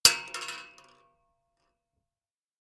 shotgun_metal_4.ogg